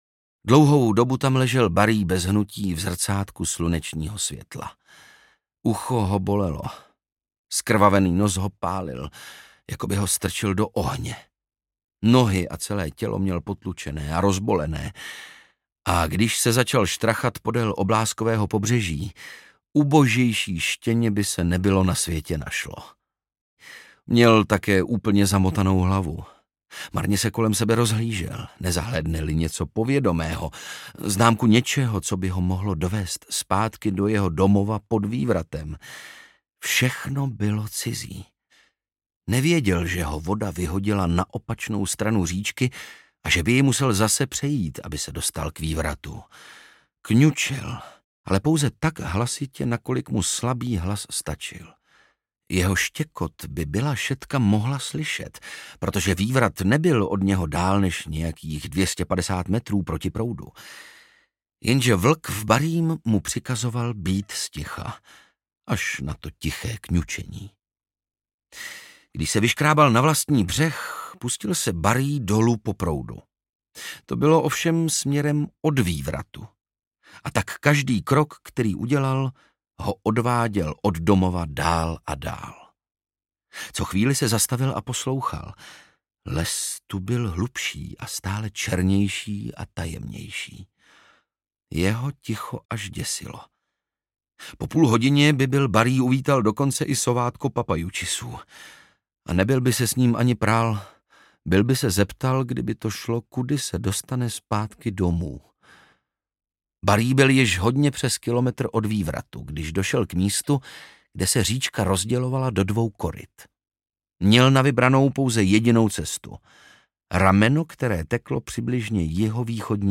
Barí, syn Kazanův audiokniha
Ukázka z knihy
• InterpretOndřej Brousek